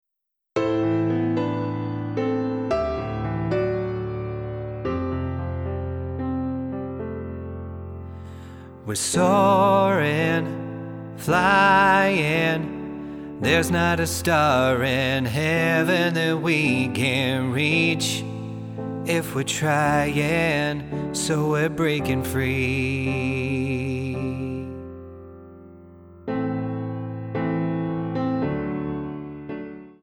Tonart:Am Multifile (kein Sofortdownload.
Die besten Playbacks Instrumentals und Karaoke Versionen .